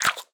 1.21.5 / assets / minecraft / sounds / mob / fox / eat1.ogg
eat1.ogg